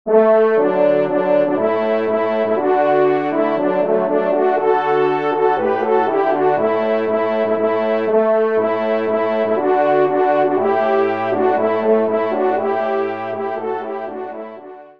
Pupitre 1° Trompe